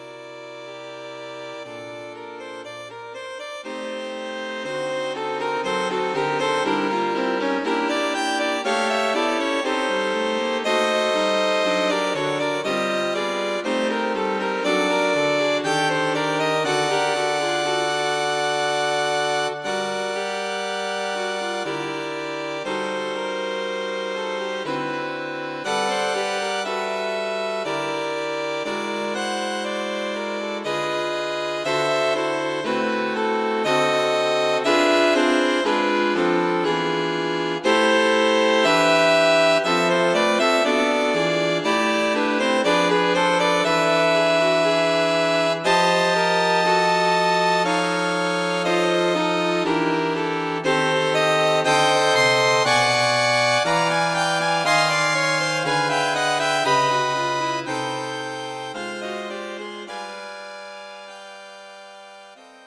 String Quintet
Two string quintets in a rich jazz ballad style.
For Violin 1, 2,3, Viola and Cello.